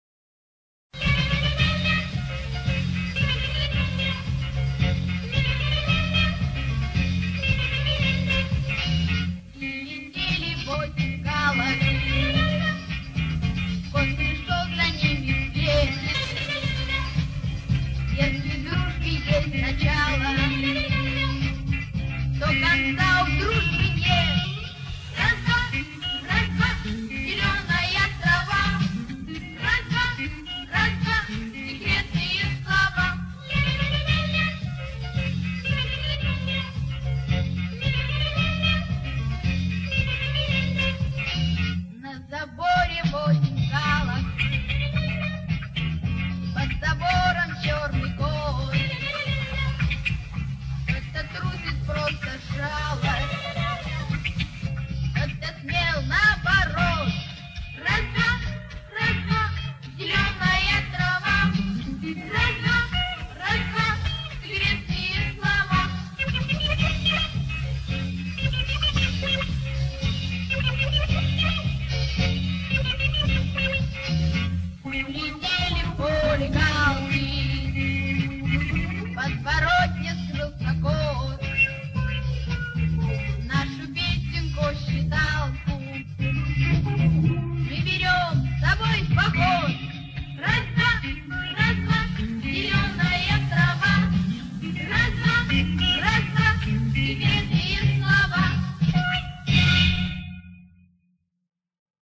Какой-то ВИА.